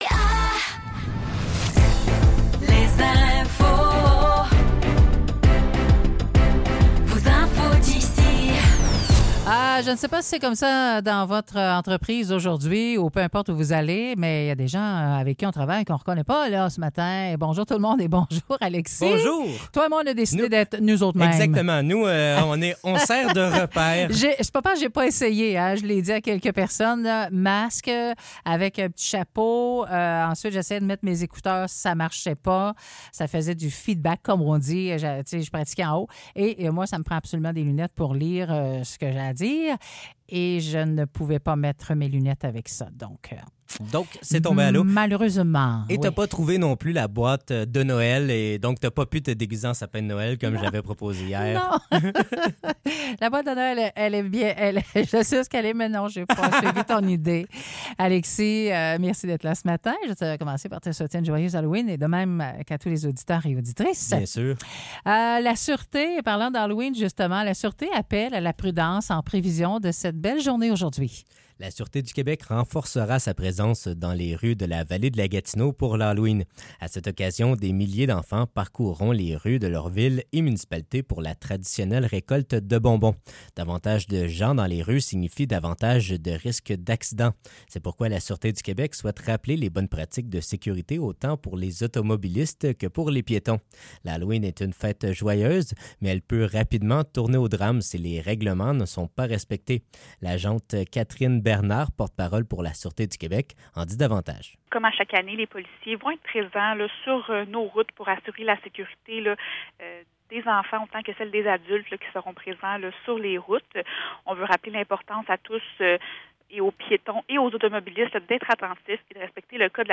Nouvelles locales - 31 octobre 2024 - 9 h